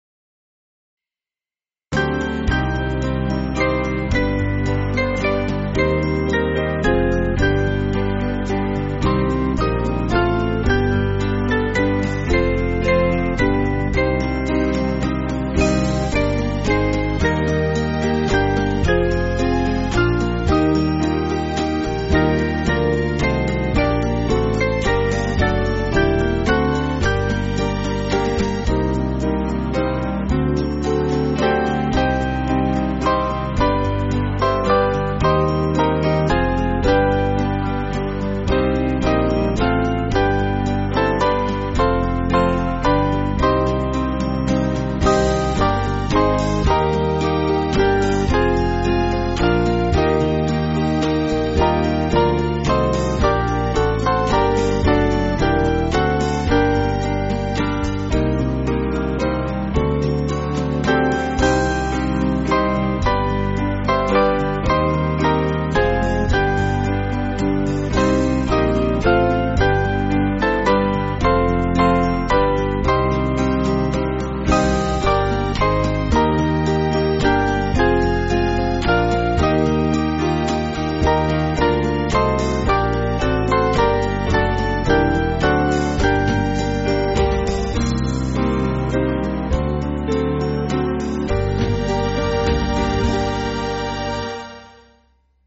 Small Band
(CM)   3/Gm